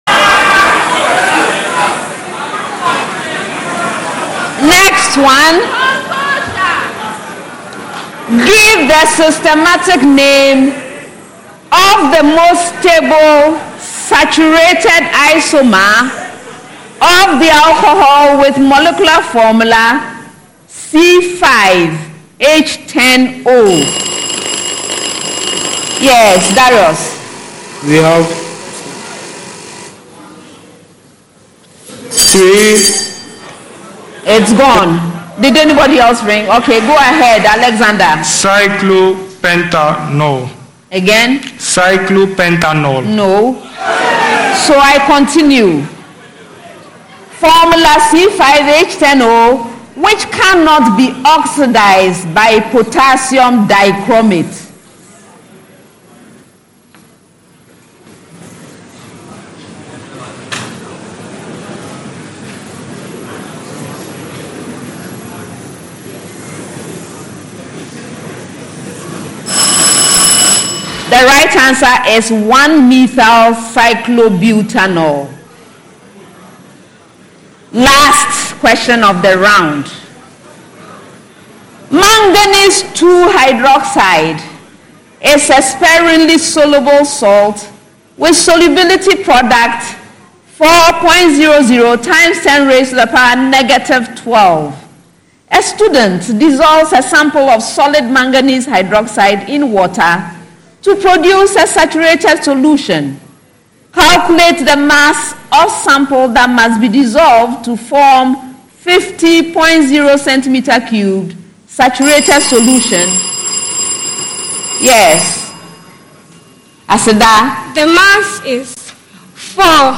What would you ask a politician if you had the chance? Welcome to The Probe, an audience-driven news interview program that collects questions from the general public and demands answers from political actors, duty bearers and relevant stakeholders.